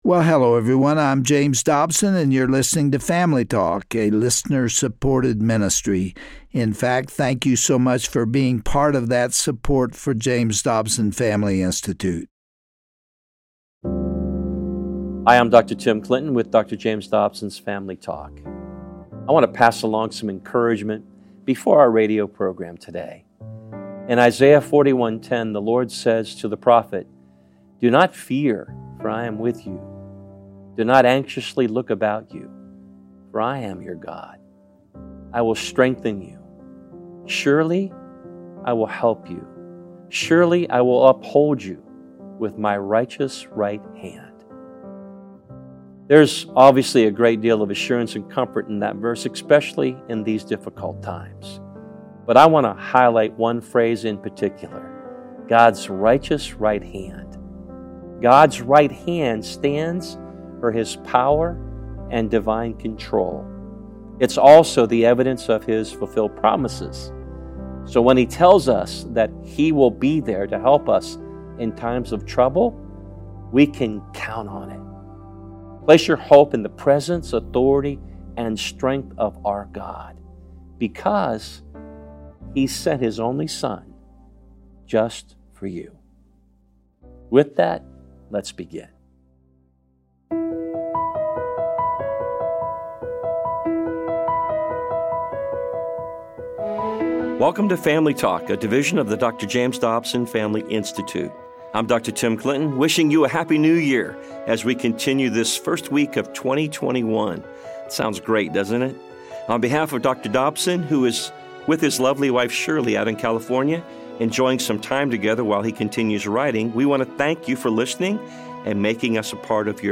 This Family Talk broadcast will offer encouragement through Dr. Dobson's classic interview with the late best-selling and marriage therapist Gary Smalley. These two men discuss the ways that pride and hurtful words eat away at relationships. Gary also emphasizes the importance of honor, respect and validation in every marriage.